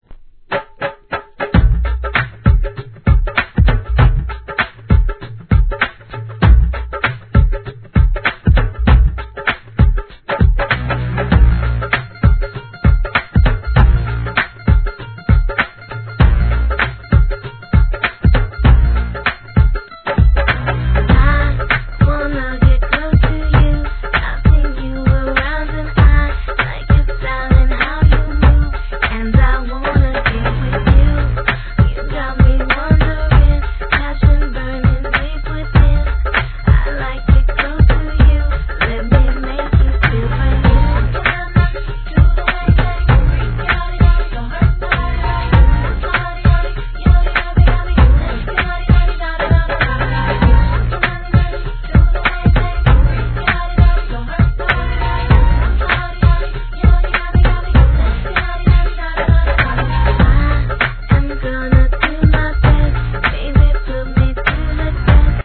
エスニックな香りをプンプン漂わすインディーなUK R&B。